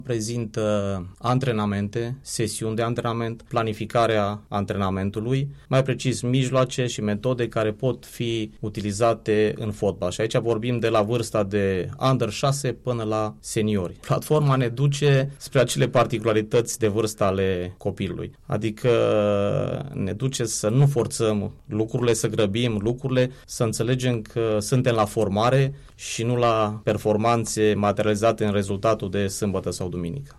Recent platforma FRF Coach a fost prezentată și la Alba Iulia, în cadrul unui eveniment care a avut loc la Universitatea ”1 Decembrie 1918” și la Baza Sportivă ”Winners Club Țălnar”.